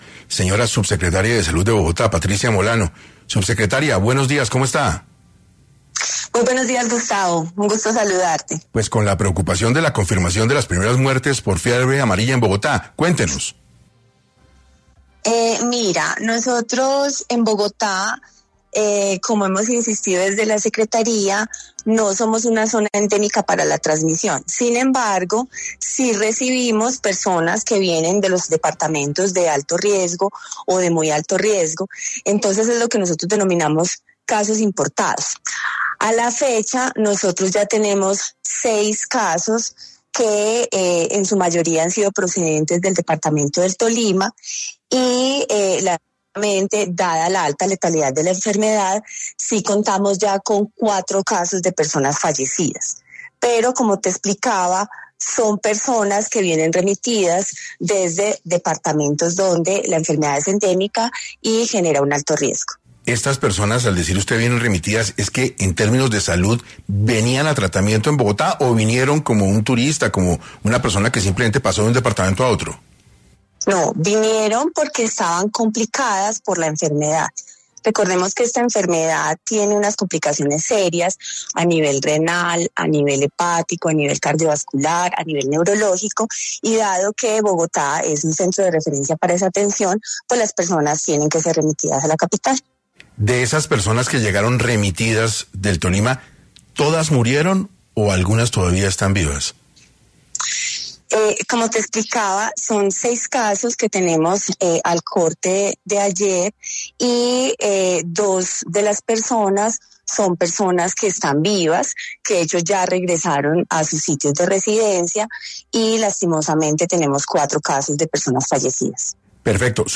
En entrevista para 6AM, Patricia Molano, Subsecretaria de Salud de Bogotá, habló sobre la crisis de salud por fiebre amarilla que vive el país ¿Hay peligro de contraerla en Bogotá?